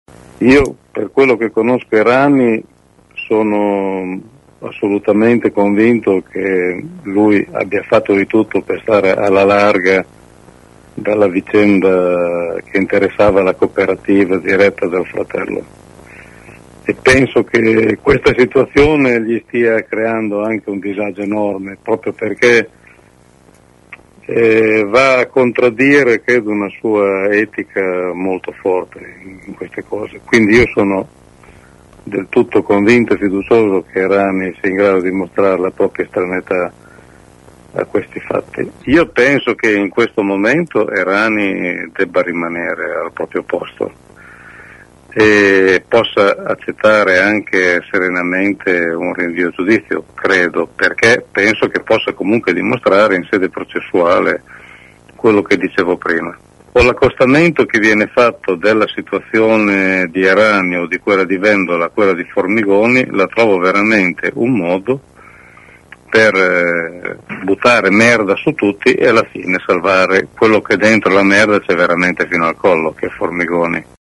“Errani può dimostrare la sua estraneità anche se rinviato a giudizio”, ha detto questa mattina, ai nostri microfoni, il capogruppo di Sel in Regione Gianguido Naldi, che accusa di barbarie politica e giuridica chi mette sullo stesso piano le indagini su Errani, Formigoni e sul presidente della Puglia Nichi Vendola, inadgato per abuso d’ufficio dalla Procura di Bari.